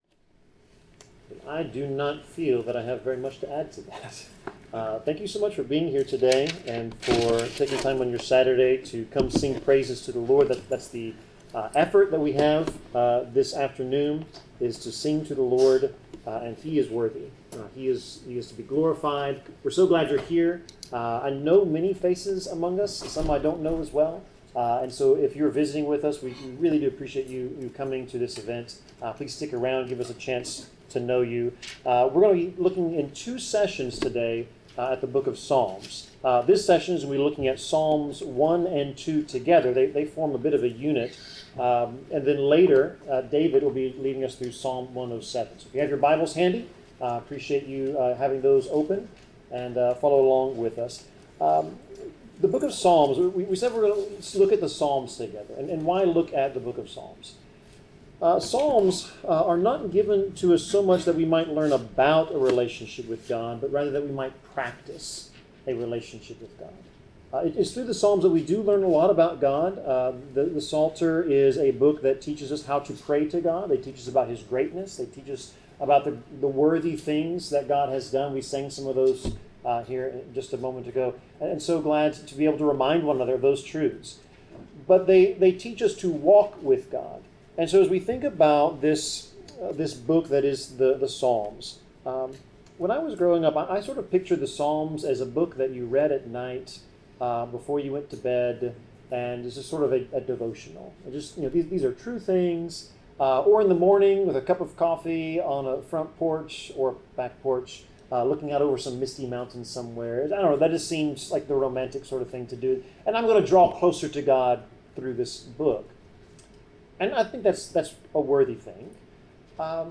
Passage: Psalms 1-2 Service Type: Sermon